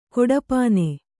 ♪ koḍapāne